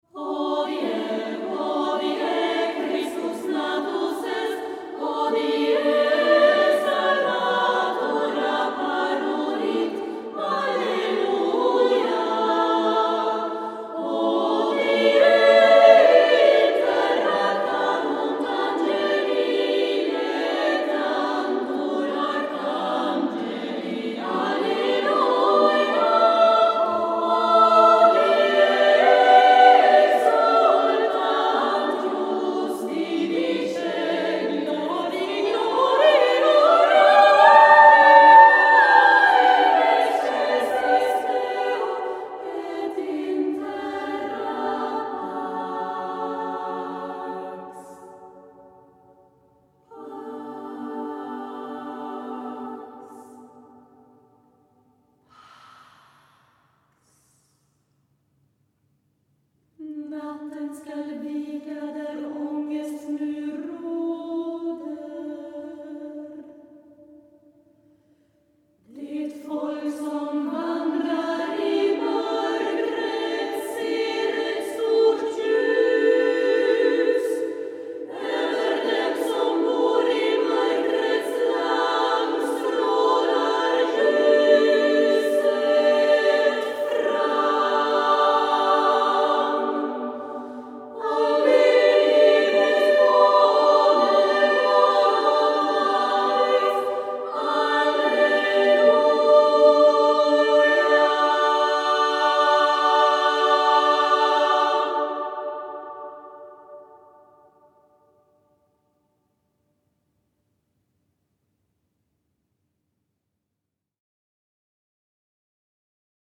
Voicing: SSAA